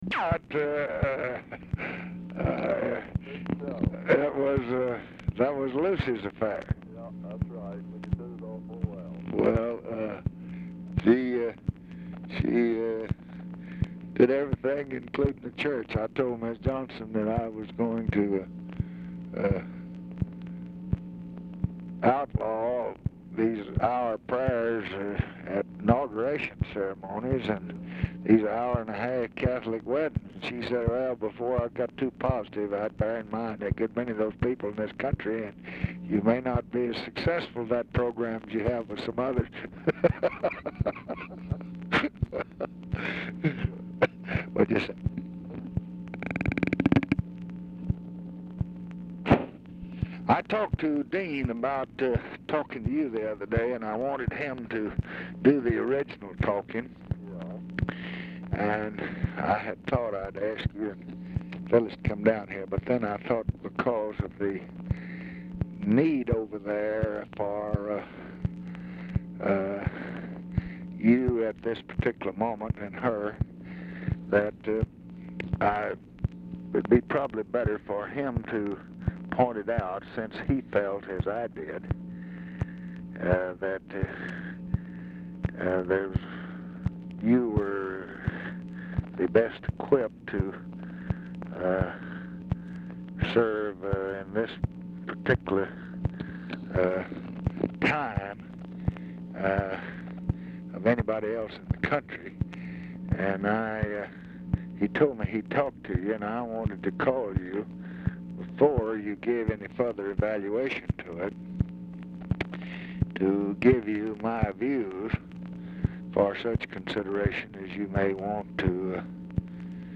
Telephone conversation # 10590, sound recording, LBJ and DOUGLAS DILLON, 8/8/1966, 12:38PM | Discover LBJ
RECORDING STARTS AFTER CONVERSATION HAS BEGUN; CONTINUES ON NEXT RECORDING; DILLON IS ALMOST INAUDIBLE
Format Dictation belt
Specific Item Type Telephone conversation Subject Appointments And Nominations Congressional Relations Diplomacy Economics International Economic Policy Johnson Family Lbj Personal Press Relations Religion Social Events Vietnam Western Europe